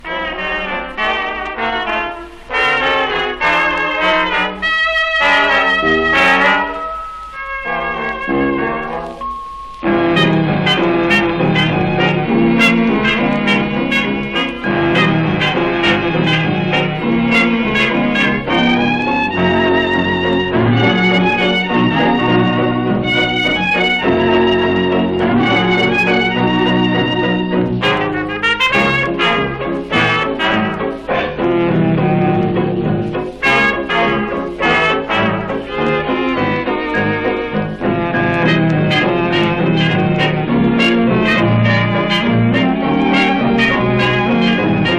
Jazz　France　12inchレコード　33rpm　Mono